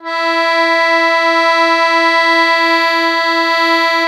MUSETTE1.5SW.wav